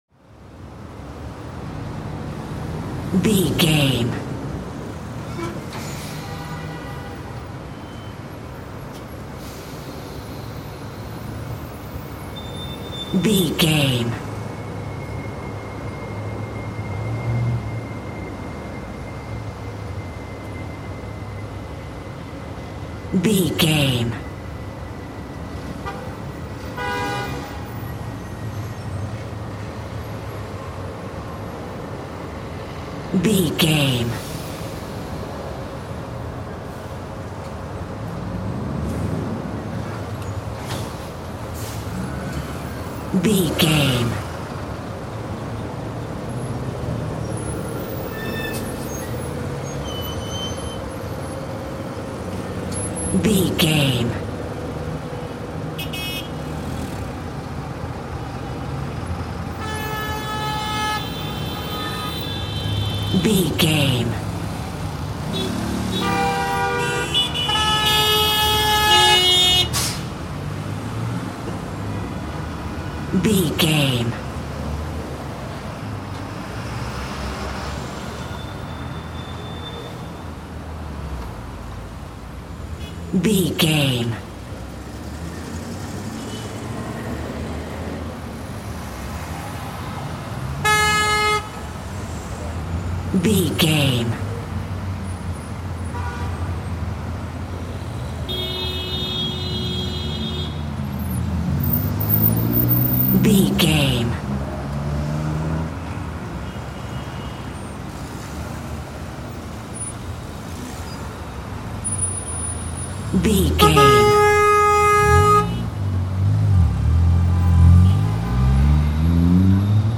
City street traffic
Sound Effects
urban
chaotic
ambience